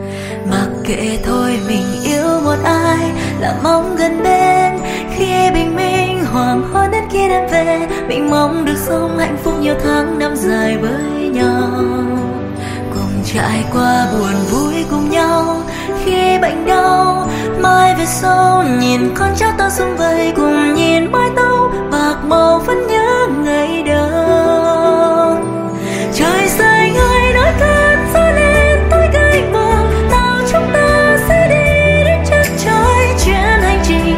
Nhạc Trẻ